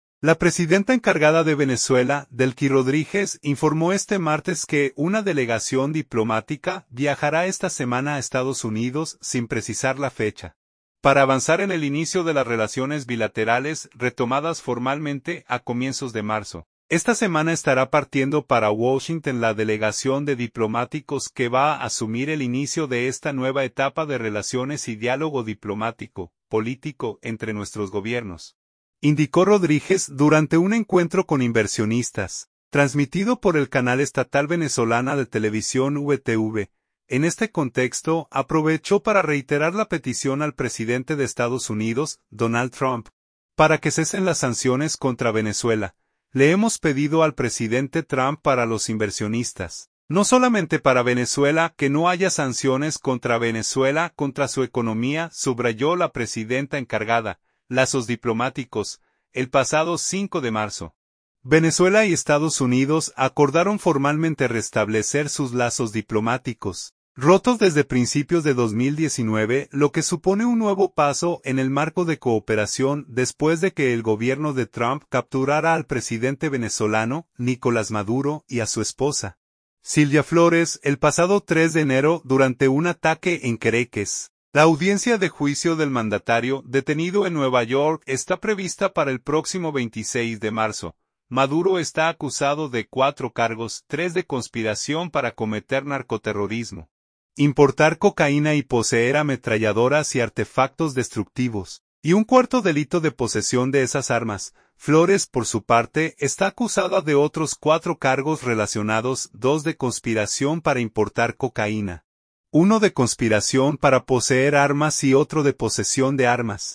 "Esta semana estará partiendo para Washington la delegación de diplomáticos que va a asumir el inicio de esta nueva etapa de relaciones y diálogo diplomático, político, entre nuestros Gobiernos", indicó Rodríguez durante un encuentro con inversionistas, transmitido por el canal estatal Venezolana de Televisión (VTV).